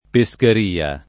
pescheria [ pe S ker & a ] s. f.